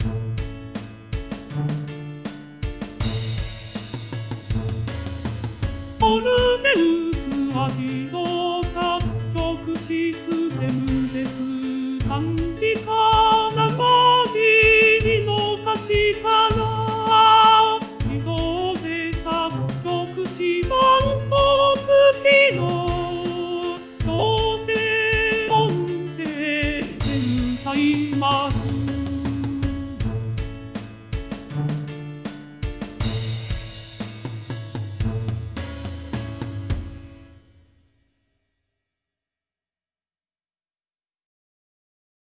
合成音声で歌います。